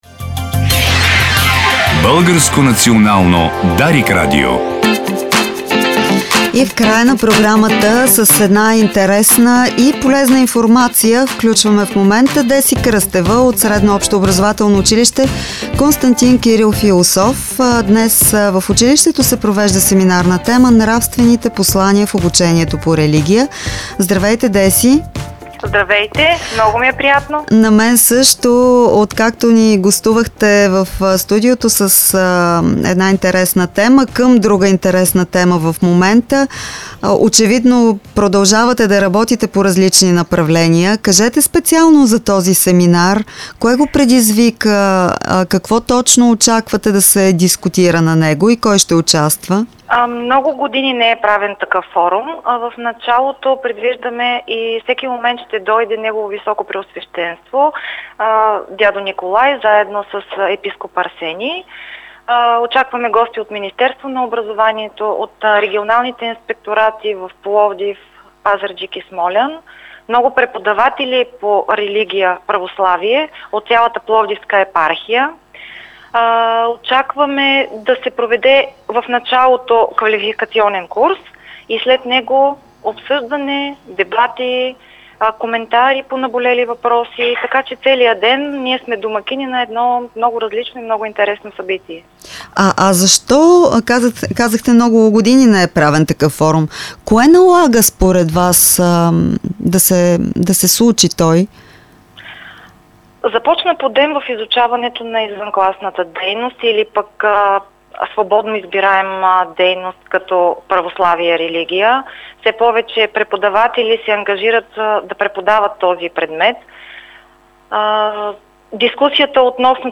Това обяви в ефира на Дарик